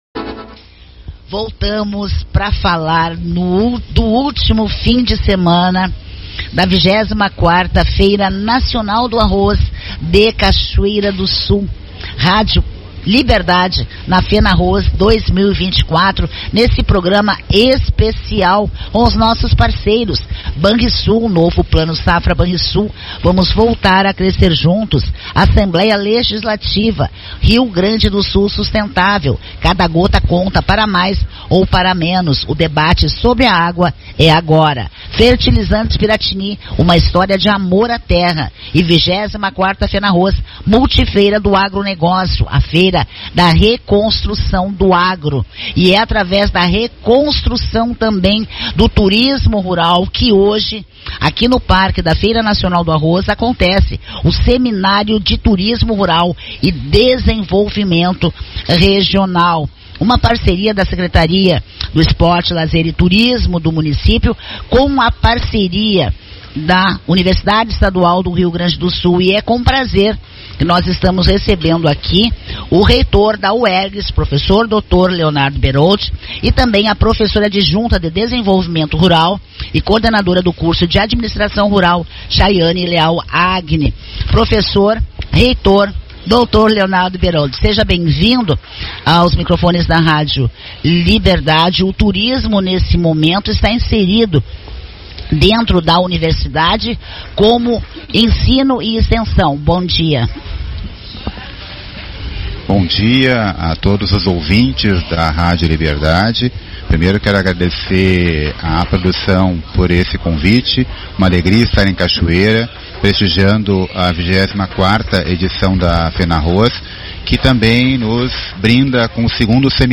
O evento, promovido em parceria com a Secretaria Municipal de Turismo, Esporte e Lazer (SMTEL), aconteceu durante a 24ª edição da Festa Nacional do Arroz (Fenarroz), no Espaço Agrotec, no Ginásio da Fenarroz.